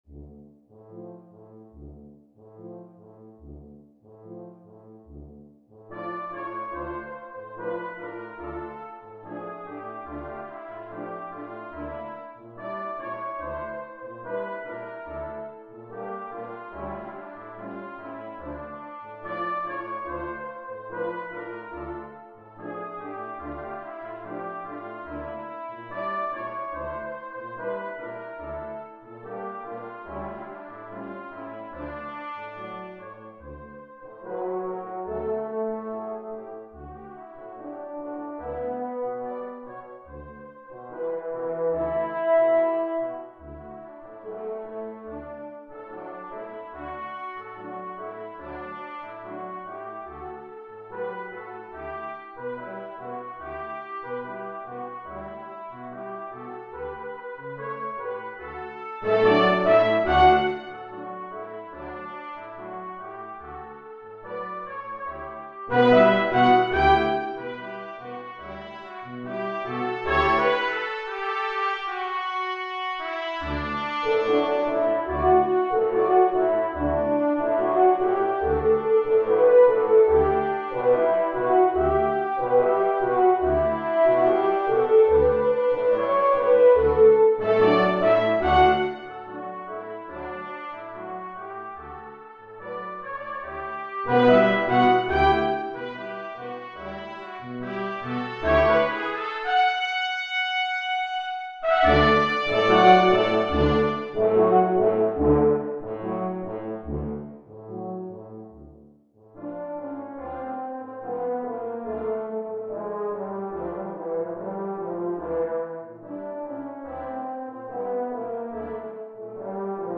【編成】金管五重奏 (2 Trumpet, Horn, Trombone, Tuba)
原調ですので、歌手との共演も可能です。